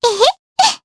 Luna-Vox_Happy2_jp.wav